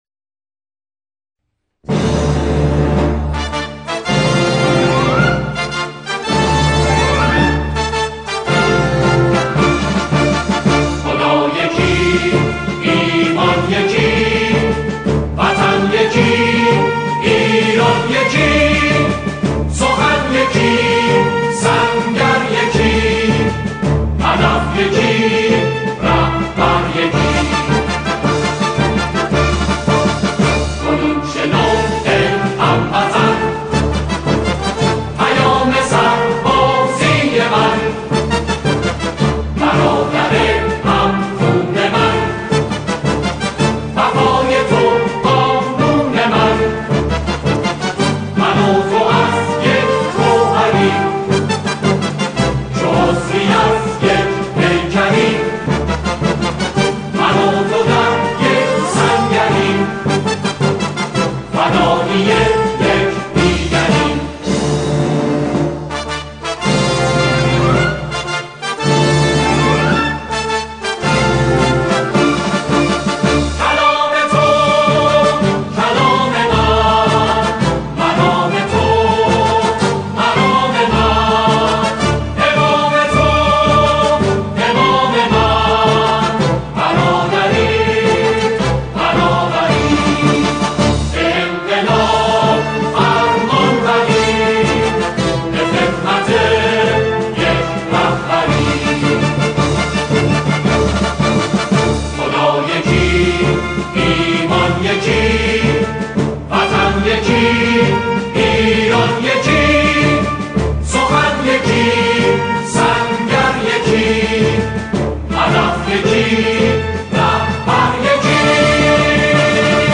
همخوانی